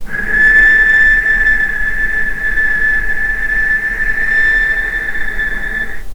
vc-A6-pp.AIF